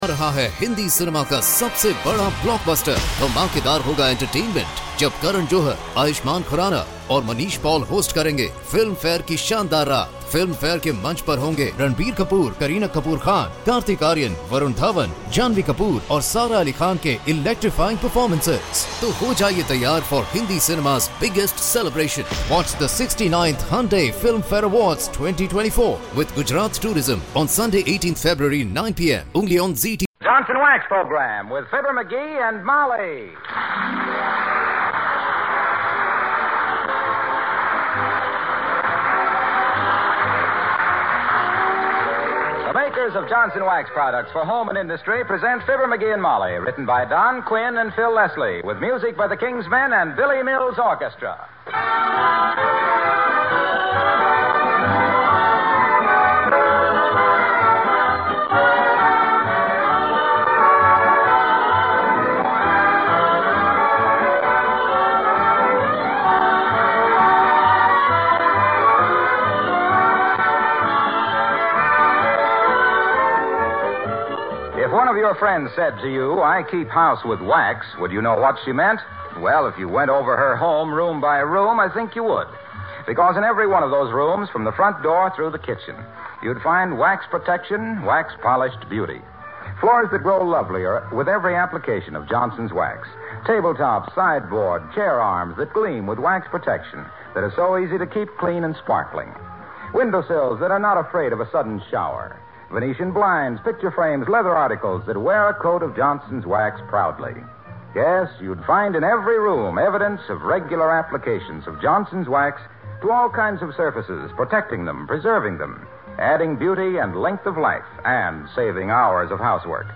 Fibber McGee and Molly was an American radio comedy series.